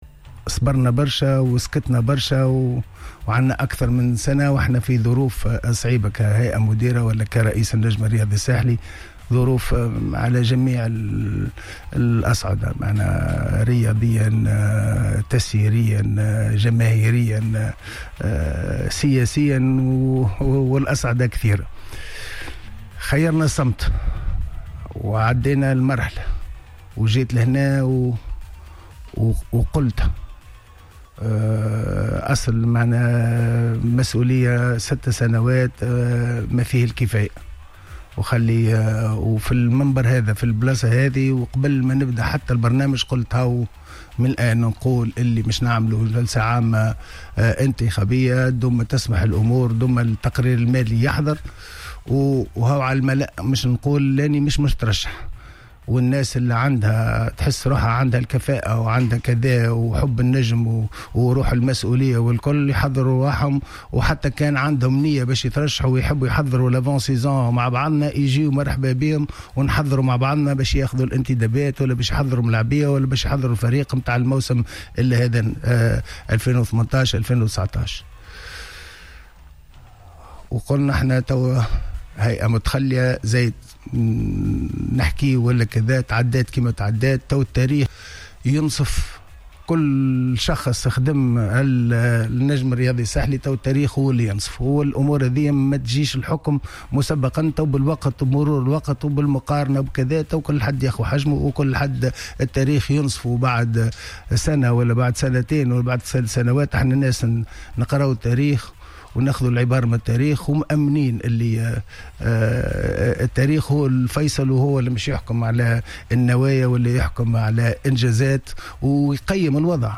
أفاد رئيس النجم الساحلي الدكتور رضا شرف الدين لدى حضوره في حصة "Planète Sport" أن هناك حملة ممنهجة تحاك ضده و بدعم من أطراف معينة.